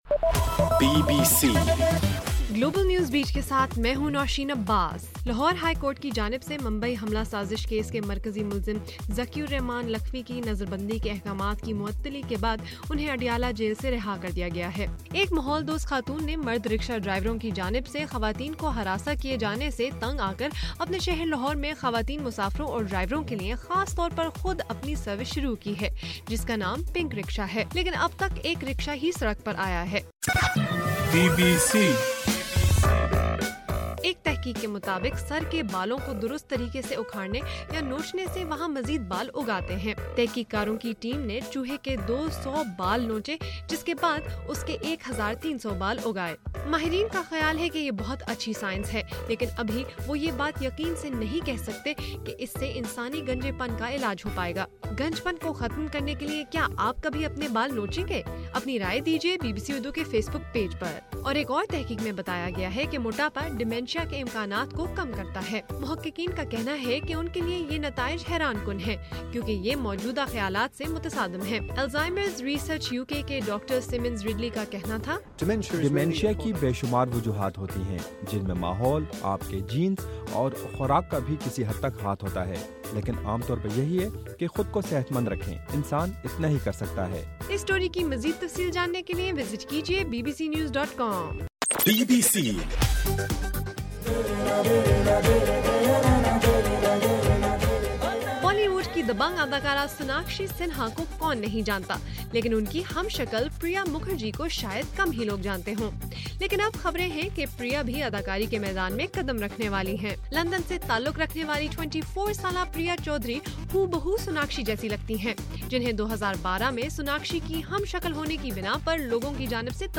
اپریل 10: رات 9 بجے کا گلوبل نیوز بیٹ بُلیٹن